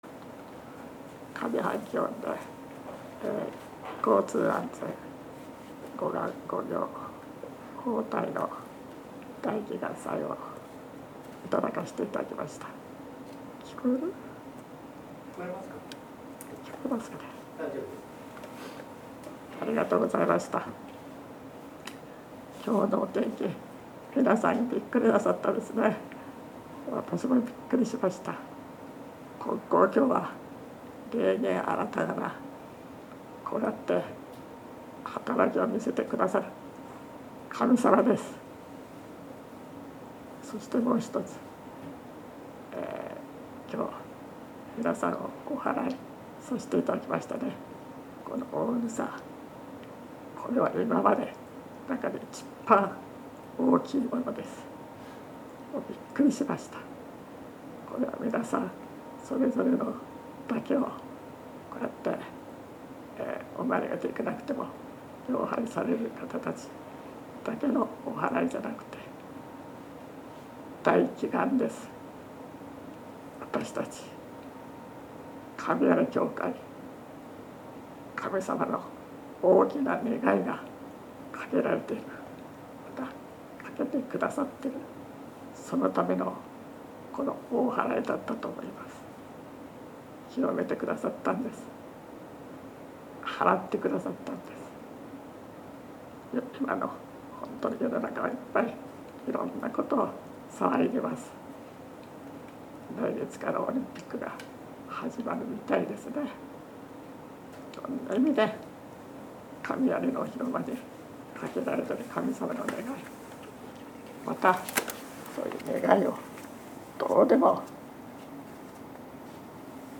大祈願祭教話